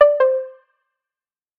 tici_disengaged.wav